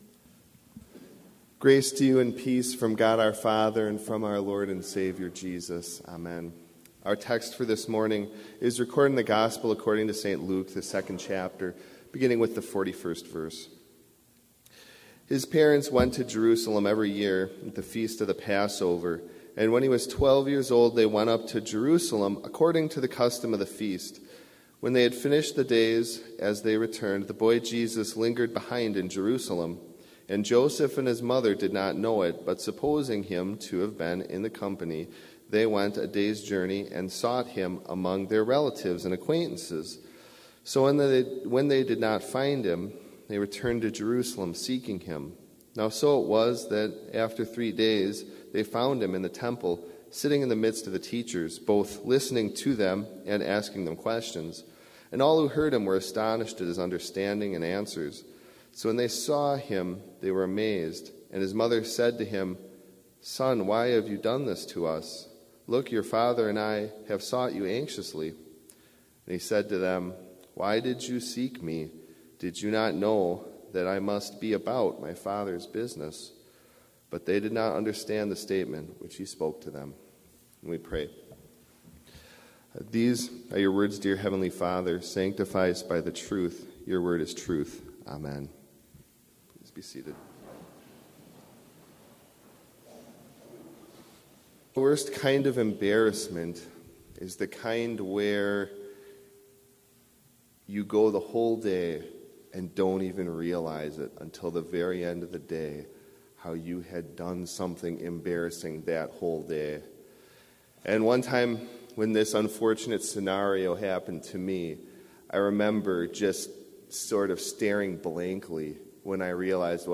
Complete service audio for Chapel - January 15, 2019